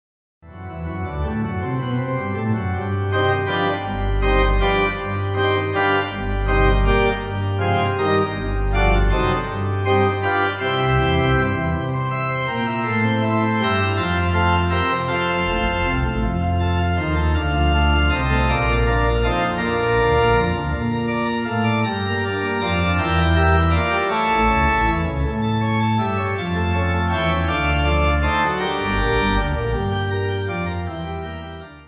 Easy Listening   F